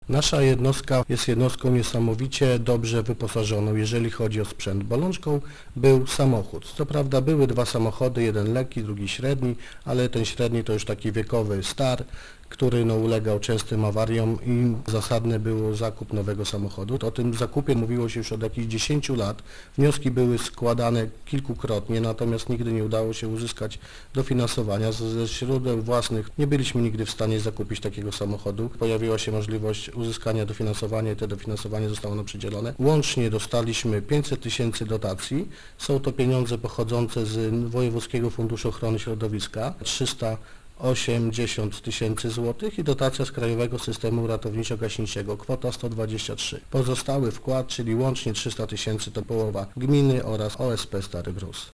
- OSP Stary Brus to jedna z trzech jednostek w gminie i jedyna, która działa w ramach Krajowego Systemu Ratowniczo-Gaśniczego - przypomina wójt gminy Stary Brus Paweł Kołtun: